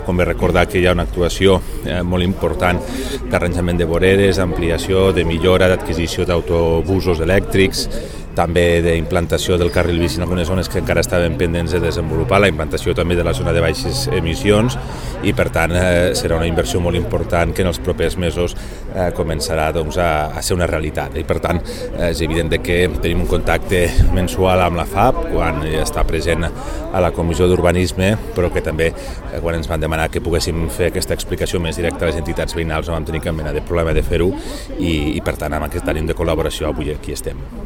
Tall de veu T.Postius